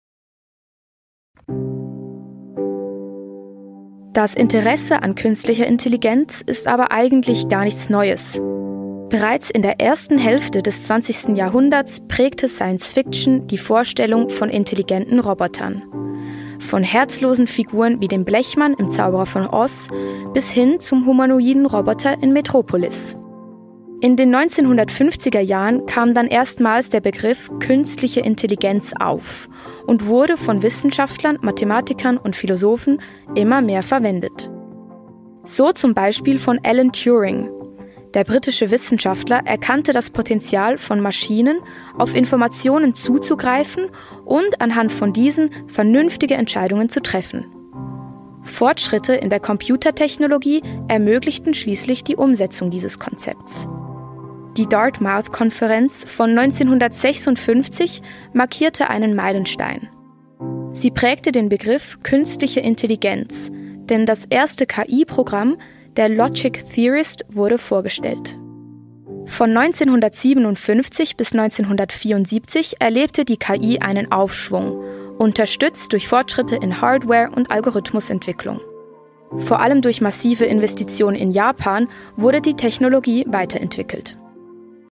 OFF-Kommentar Hochdeutsch (CH)